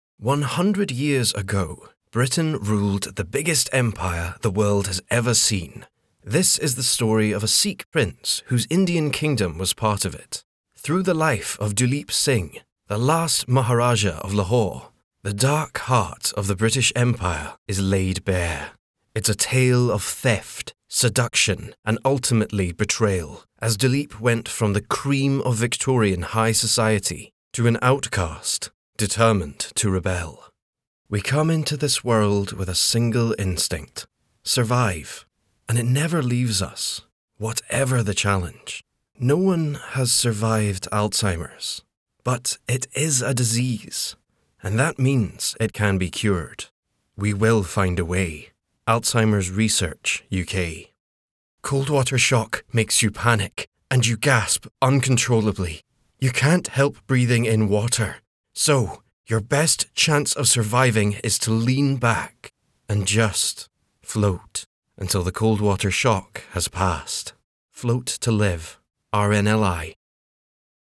Narration Reel
• Native Accent: Scottish
• Home Studio
He, too, can access a smooth, trustworthy quality for that high-end commercial.